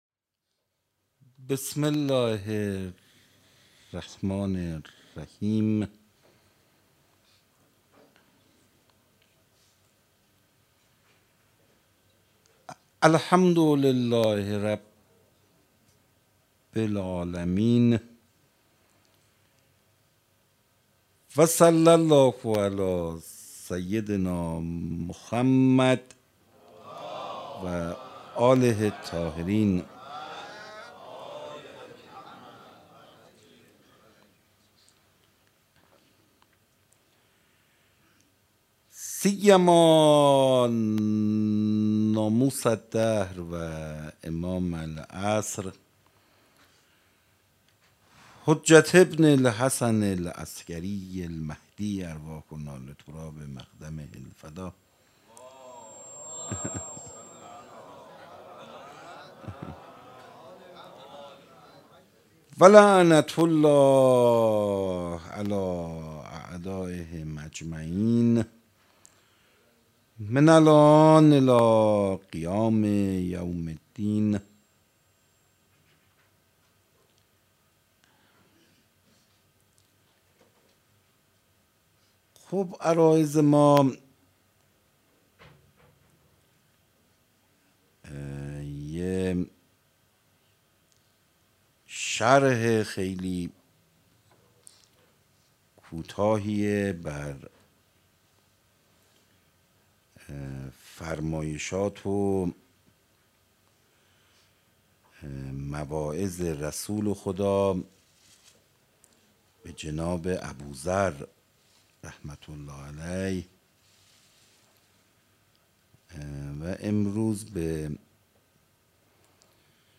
سخنرانی روز ششم